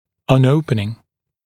[ɔn ‘əupnɪŋ][он ‘оупнин]при открывании, при открытии